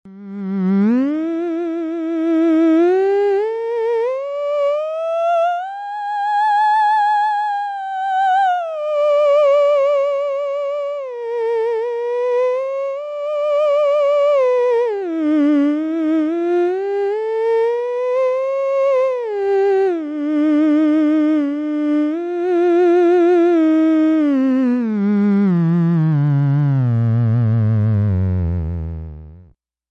Tell me if it has a tube sound after a couple of listening passes, I add in circuit distortion to make it a little buzzy, hopefully like the original RCA I think.
Below is a direct feed, Radio Shack $2.19 OP Amp (TL082) fed into my cheap computer sound card.
The sound is nice, although it seems to have some kind of distortion at times (not all the time, but when a note has been sustained for a while, apparently).
I hear a whispering "zzzzhhhh" sound accompanying the melody from beginning to end.